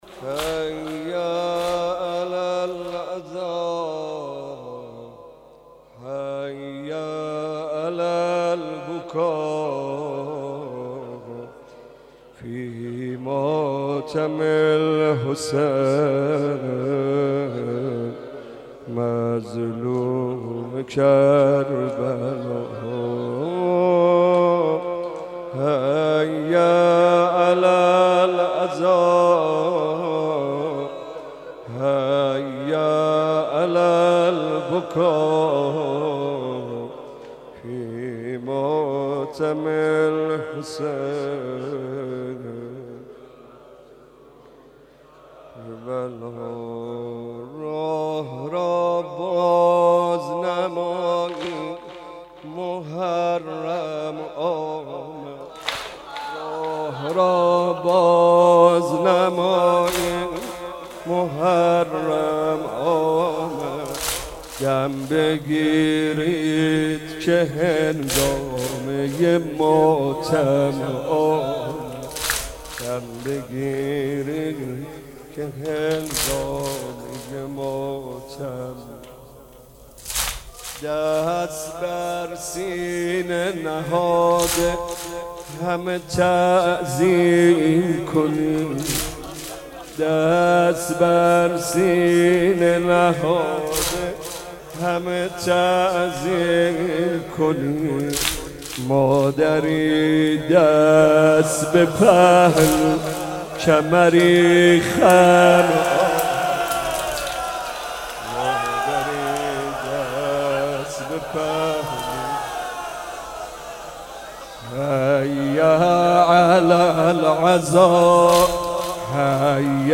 محمدرضا طاهریمداح